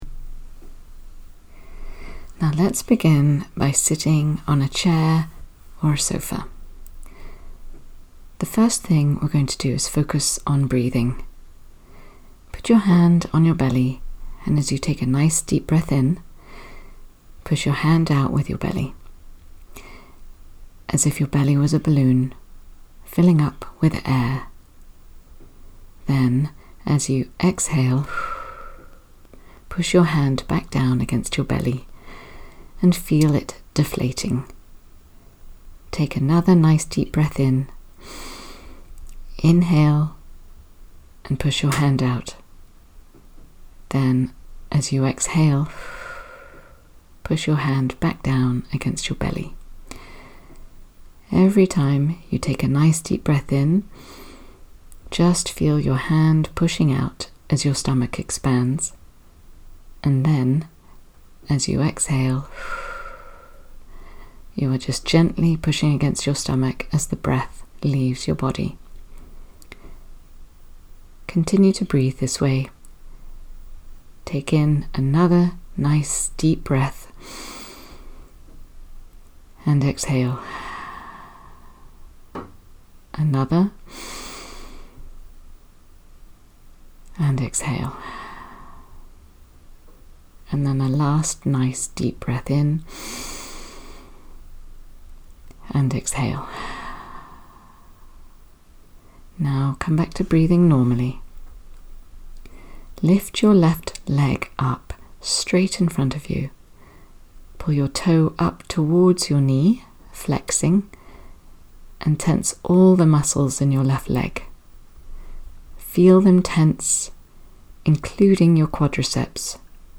Below are some recordings I have made to guide in mindfulness and further down, a sample of hypnotherapy.
Progressive relaxation exercise (9:15 mins)
Progressive-relaxation.mp3